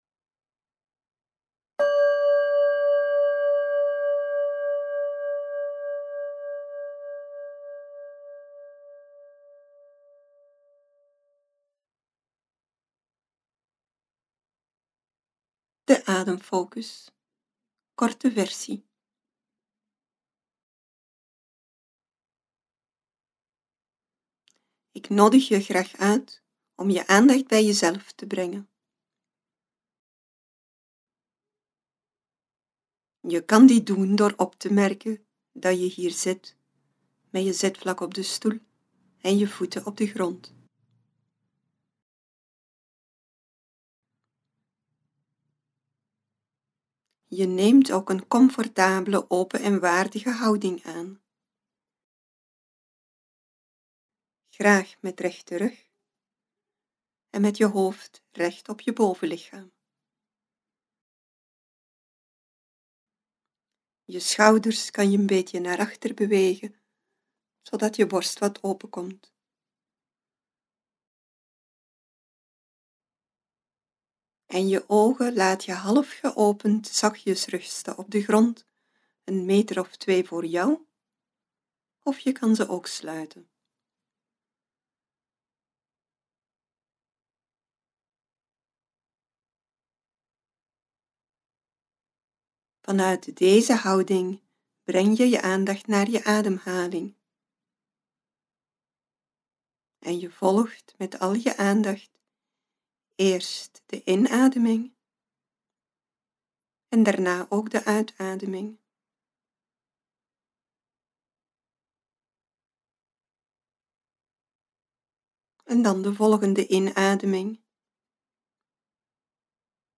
Beluister hier een audiofragment van onze enthousiaste trainer. Noot: Je zet je hiervoor bij voorkeur op een stoel, maar elke andere houding, zelfs liggend, is prima!
02 Ademfocus kort.mp3